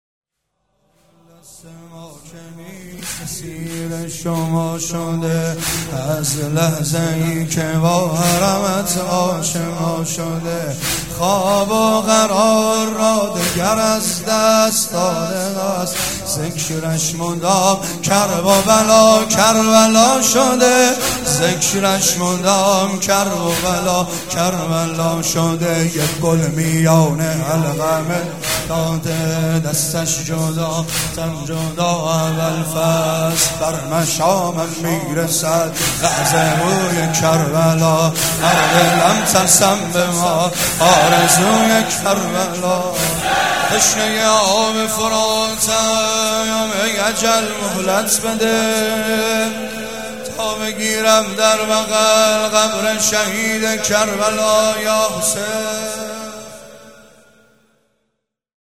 شهادت امام جواد (ع)